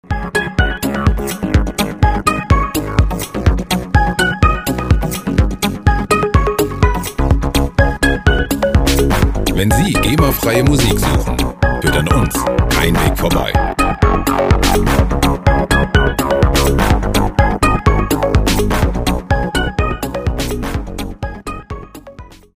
Electro Loops
Musikstil: Electro
Tempo: 125 bpm